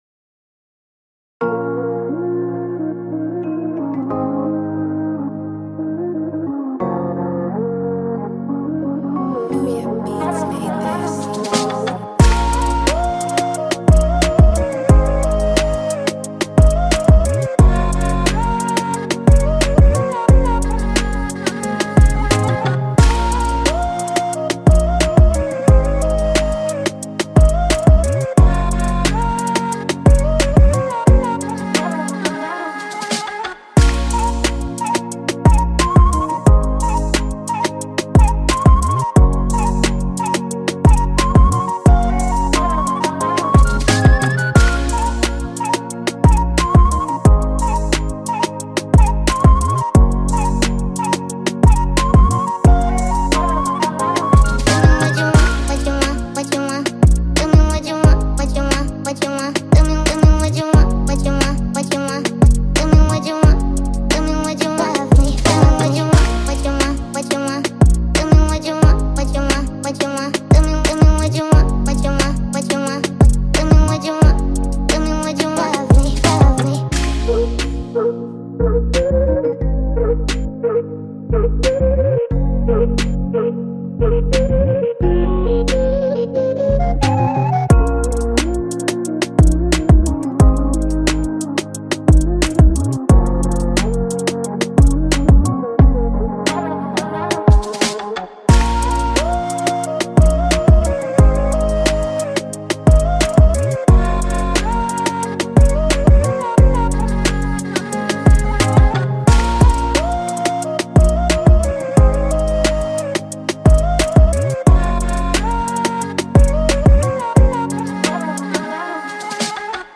Trap Oriental Beat x Balkan Hip Hop Instrumental